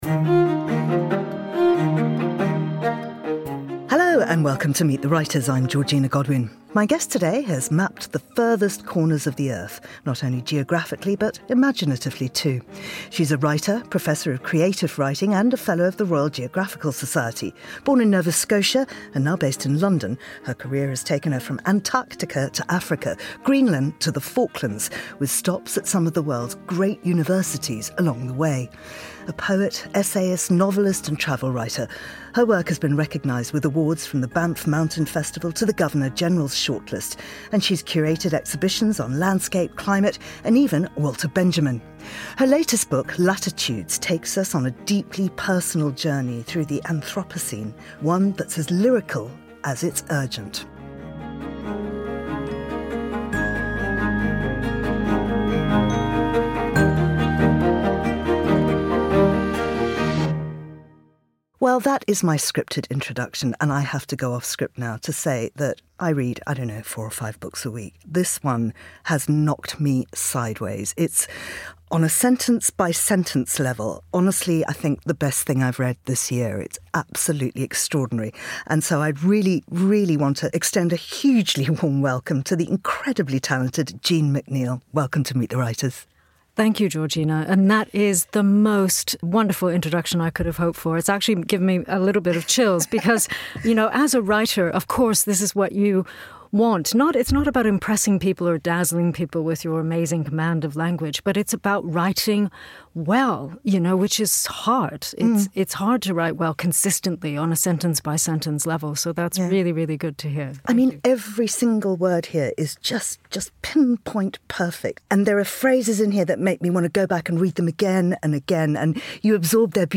riveting interview